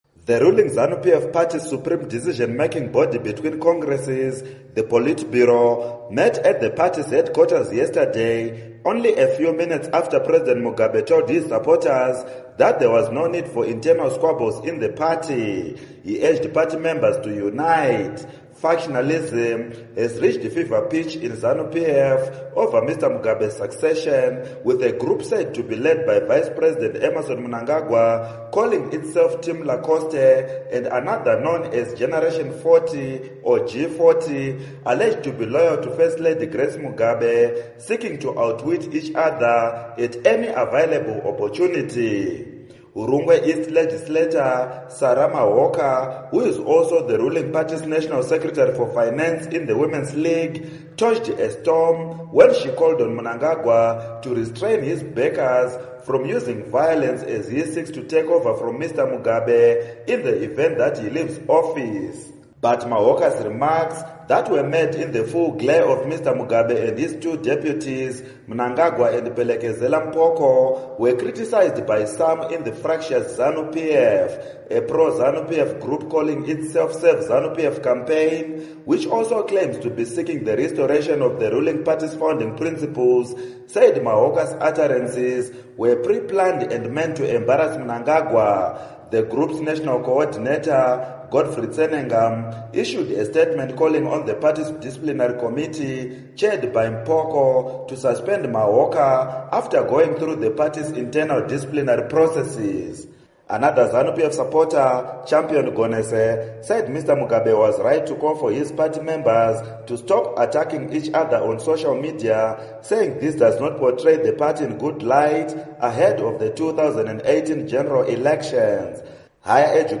Report on Mugabe Party Speech